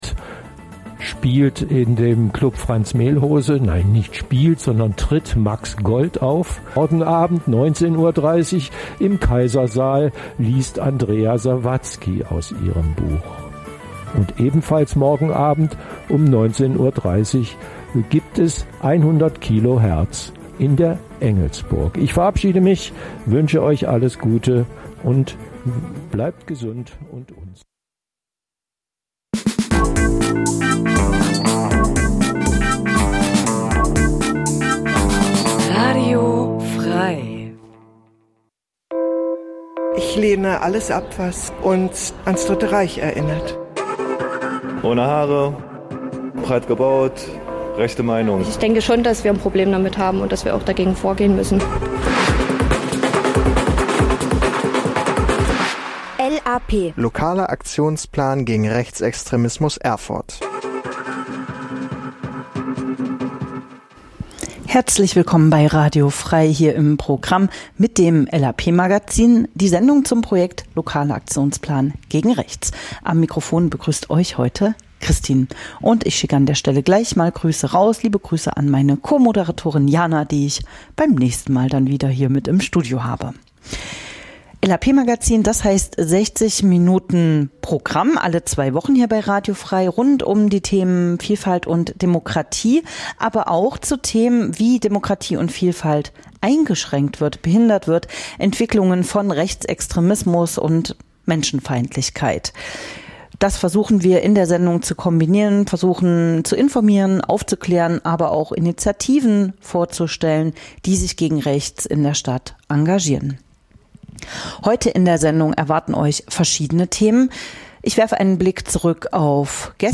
Kurzinterviews